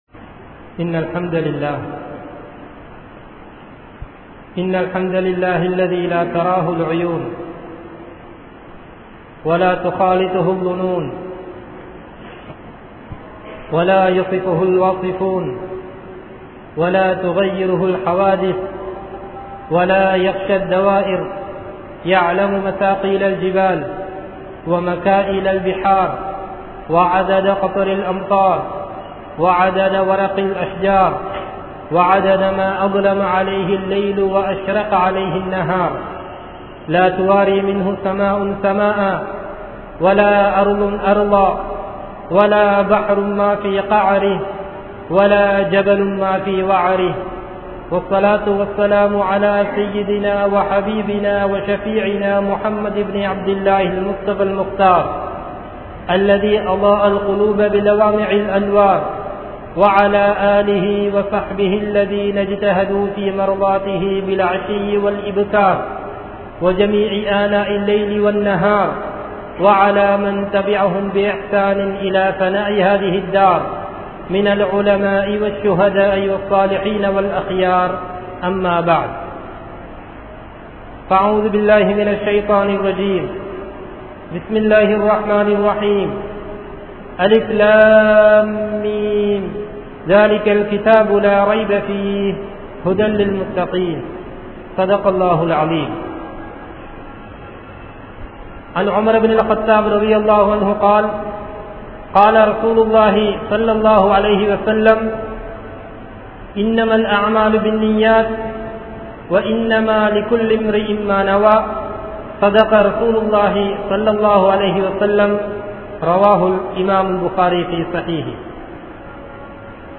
Ottrumaiyaaha Vaalungal (ஒற்றுமையாக வாழுங்கள்) | Audio Bayans | All Ceylon Muslim Youth Community | Addalaichenai
Muhiyaddeen Grand Jumua Masjith